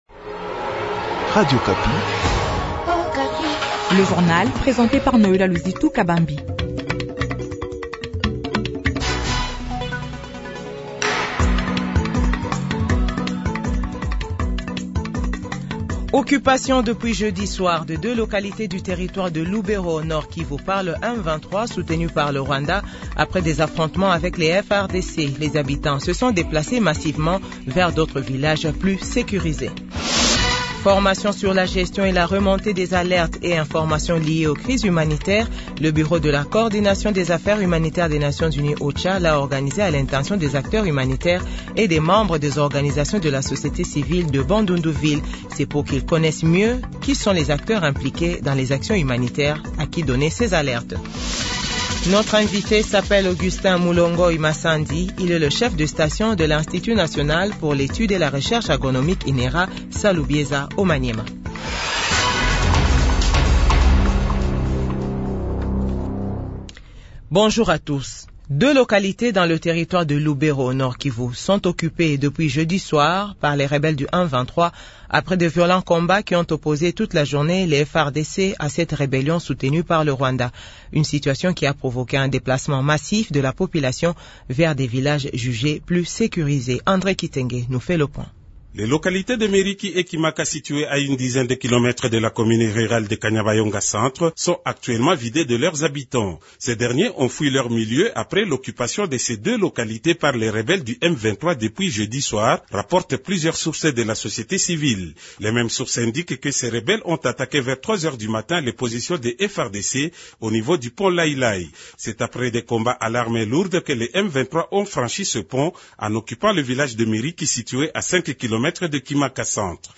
JOURNAL FRANCAIS 15H00